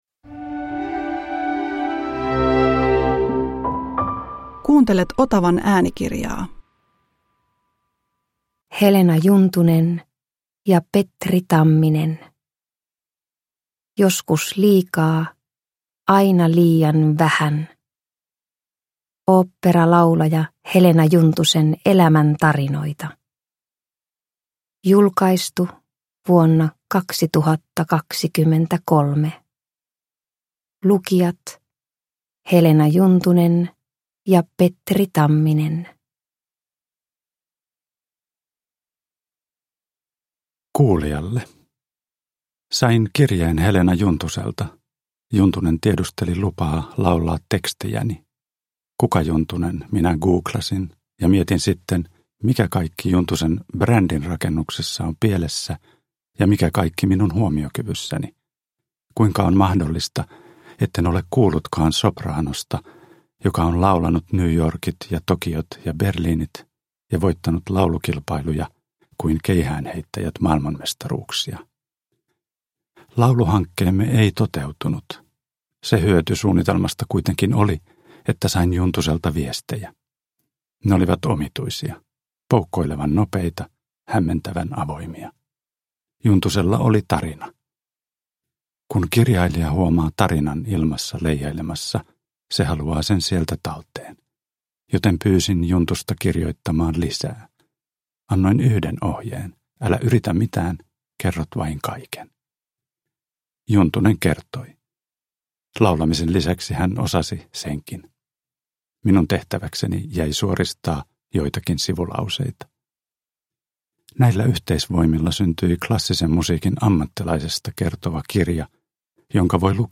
Joskus liikaa, aina liian vähän – Ljudbok – Laddas ner
Uppläsare: Petri Tamminen, Helena Juntunen